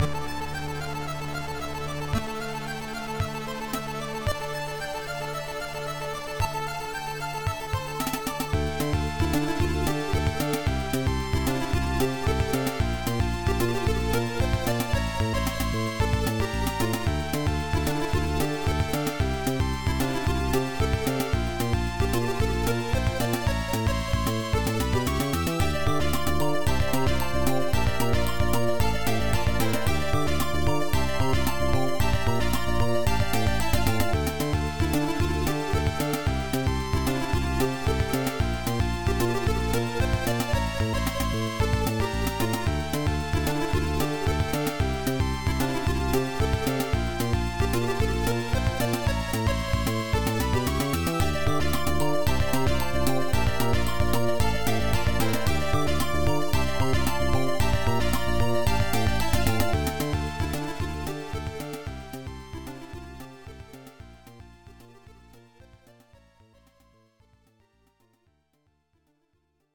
rifatto in chiave MSX + OPLL.